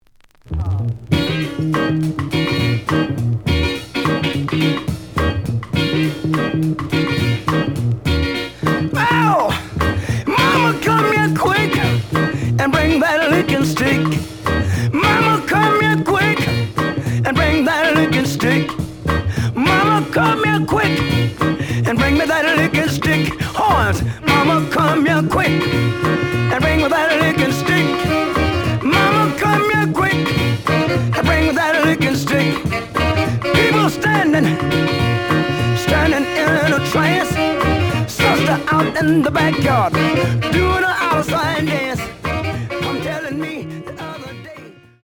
The audio sample is recorded from the actual item.
●Genre: Funk, 60's Funk